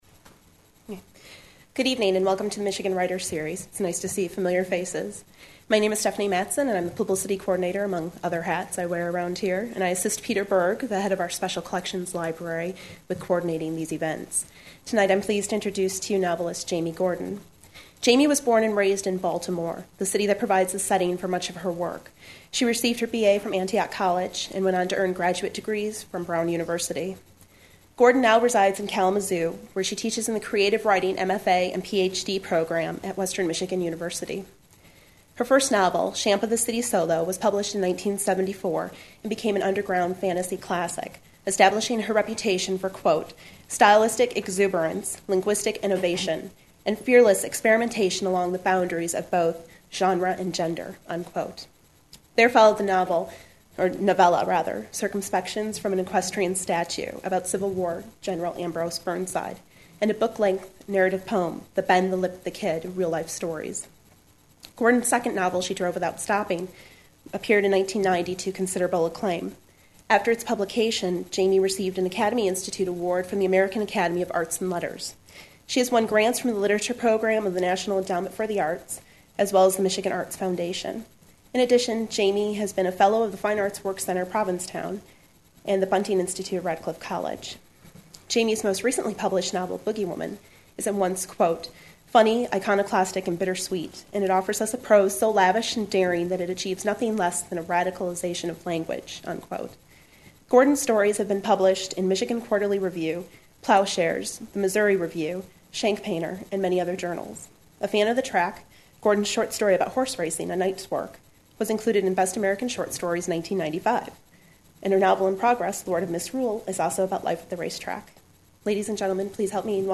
Jaimy Gordon, professor of creative writing at Western Michigan University, reads from her novel in progress "Lord of misrule" and answers questions from audience at the Michigan Writers Series